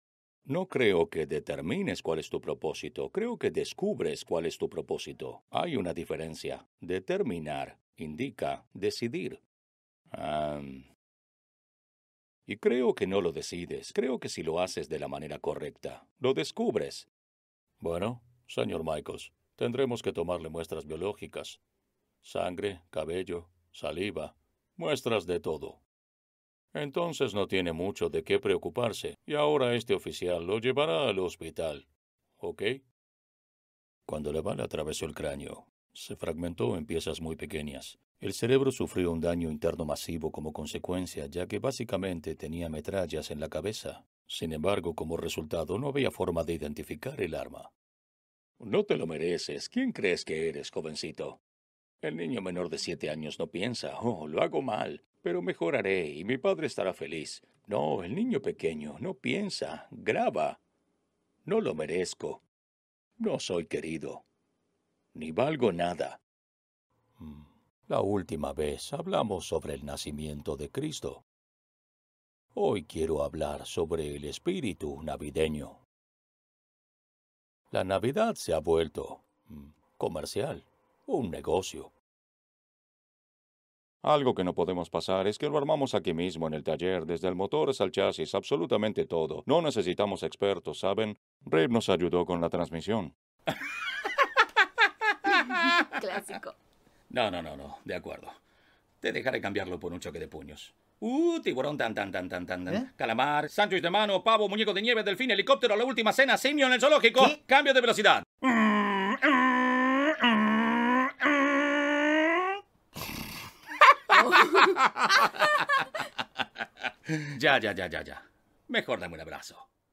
Poseo estudio propio con calidad profesional, y me autobgrabo para varios estudios.
Sprechprobe: Werbung (Muttersprache):